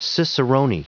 Prononciation du mot cicerone en anglais (fichier audio)
Prononciation du mot : cicerone